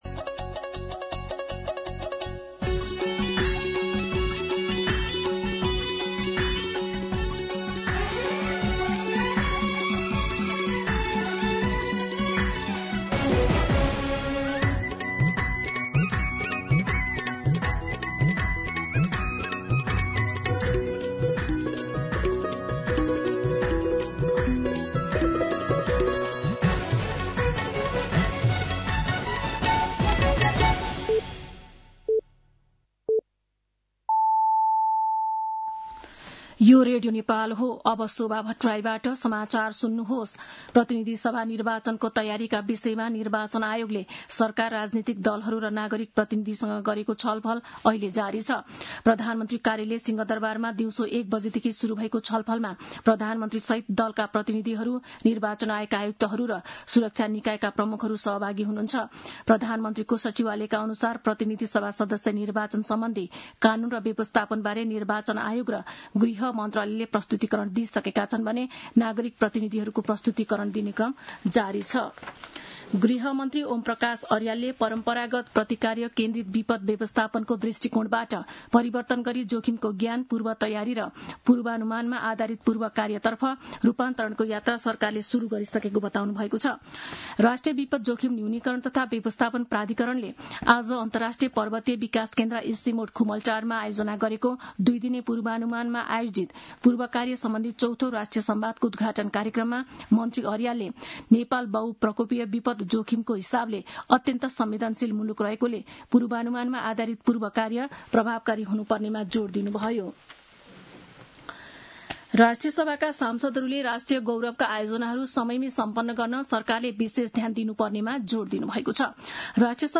An online outlet of Nepal's national radio broadcaster
दिउँसो ४ बजेको नेपाली समाचार : ७ पुष , २०८२
4pm-Nepali-News-9-7.mp3